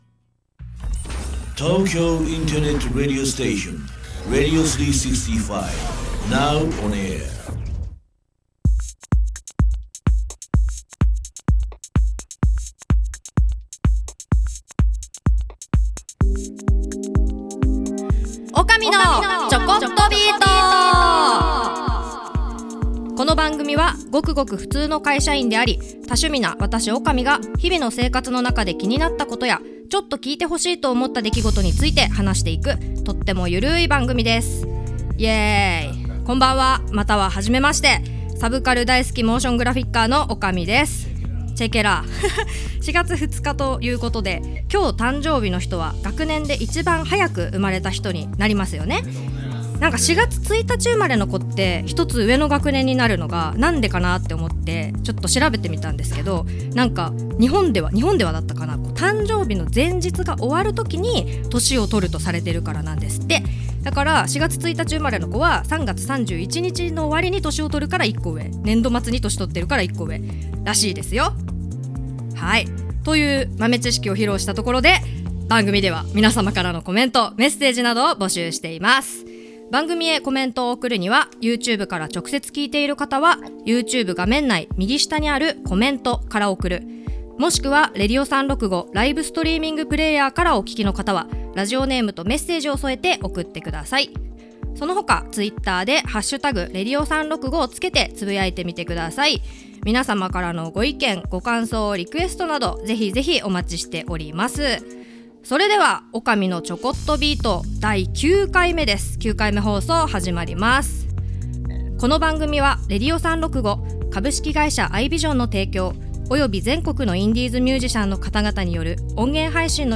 Vol.09～2023年4月2日生放送アーカイブ